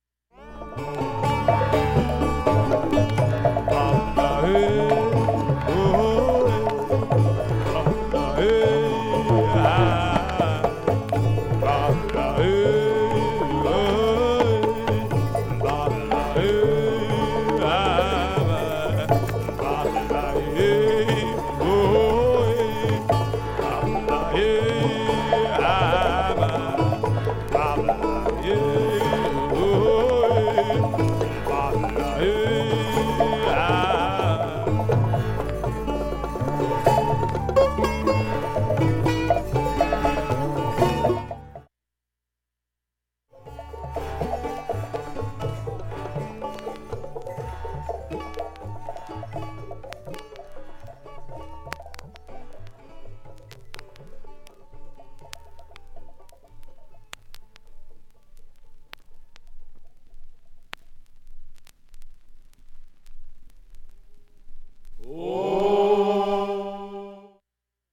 影響無し音質良好全曲試聴済み
1,B-1中盤にかすかなプツが１８回出ます。
かすかなプツが７回出ます。
スピリチュアル・ジャズ名盤